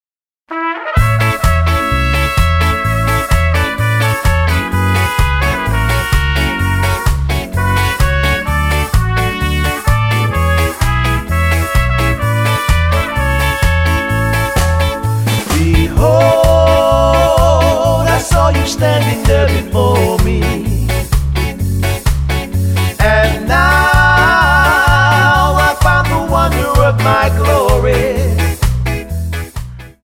Tonart:F Multifile (kein Sofortdownload.
Die besten Playbacks Instrumentals und Karaoke Versionen .